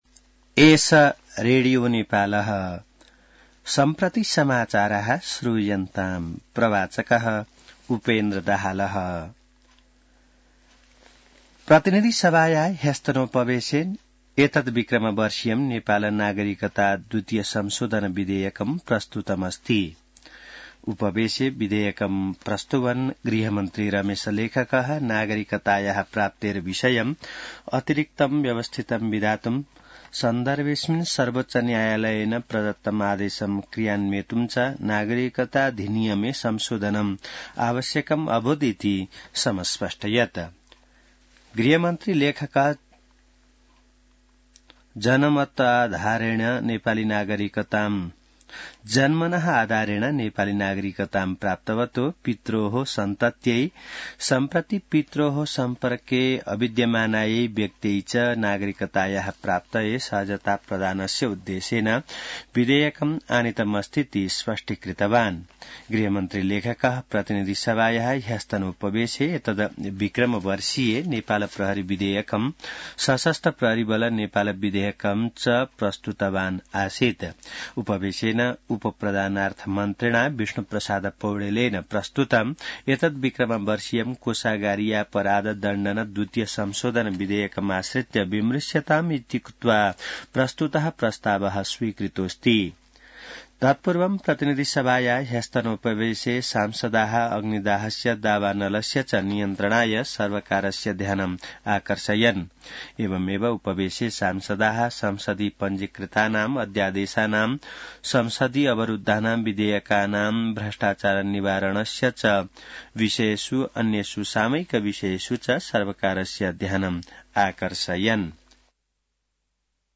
संस्कृत समाचार : २९ माघ , २०८१